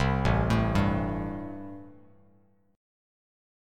Am7 Chord
Listen to Am7 strummed